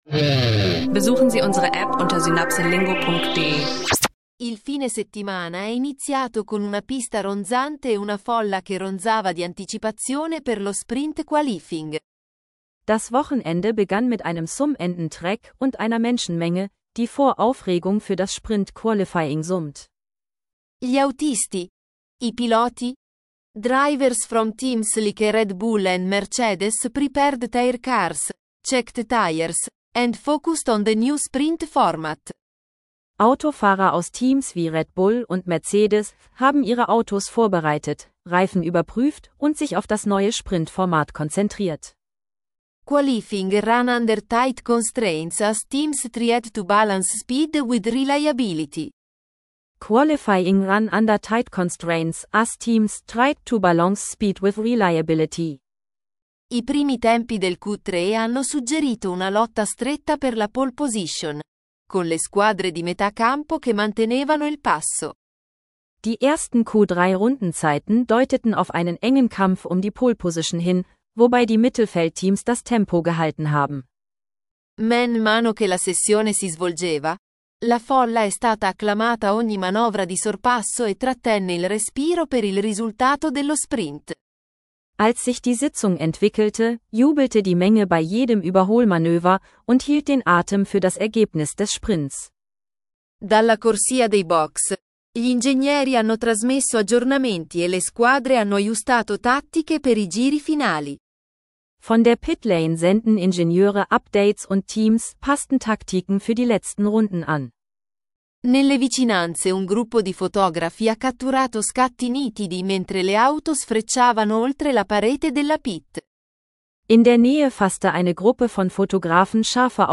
Lerne Italienisch mit praxisnahen Dialogen rund um Sprint-Qualifying, F1-Drama und schnelle Fahrzeugupdates – perfekt für Italienisch lernen online.